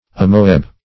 amoebae.mp3